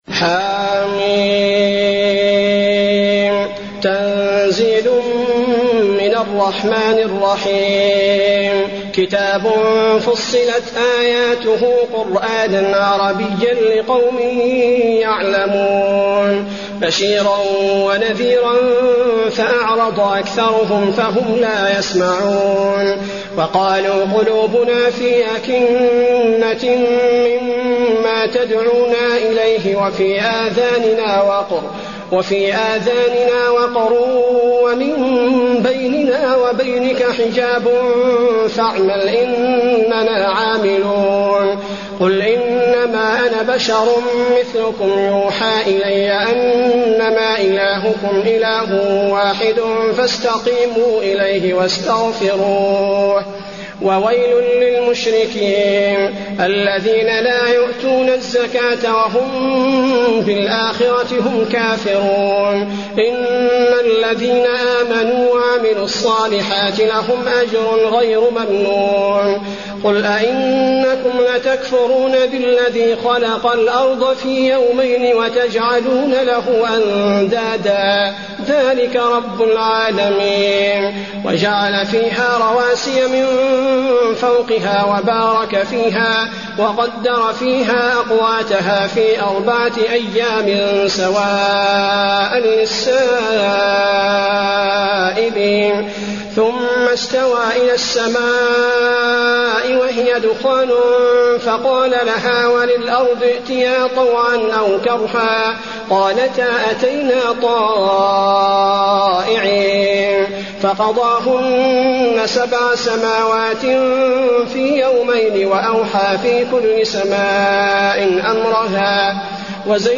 المكان: المسجد النبوي فصلت